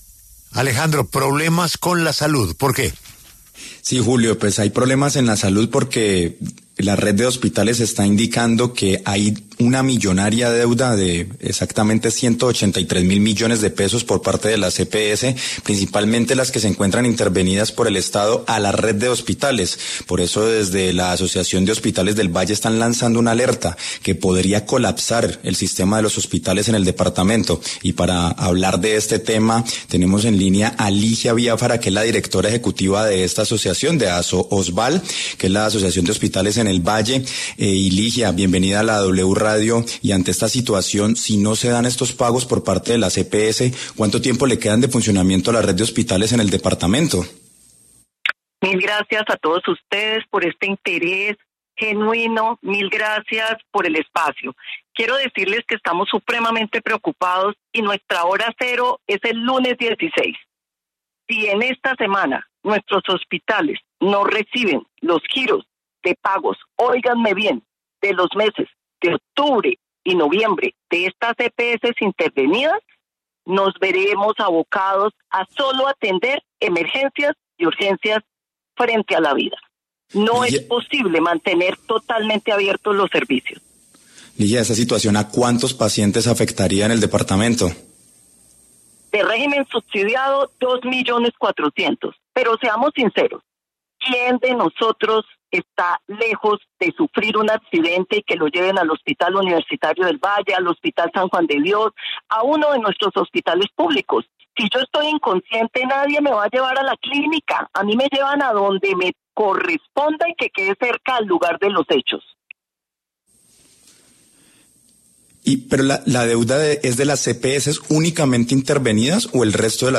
conversó con La W, con Julio Sánchez Cristo